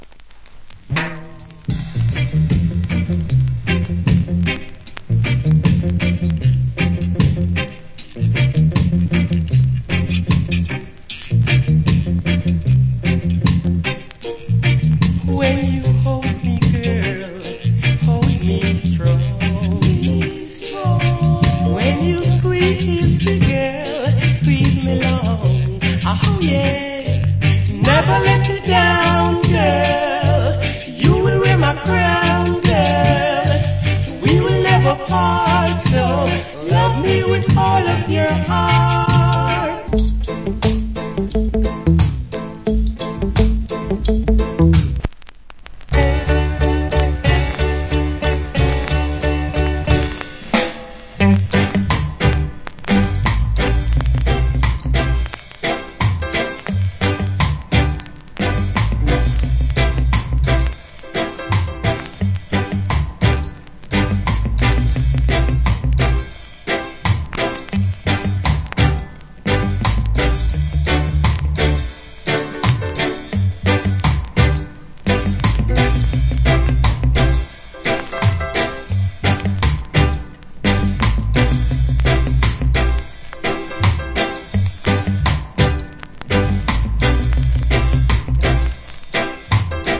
Nice Old Hits Medley.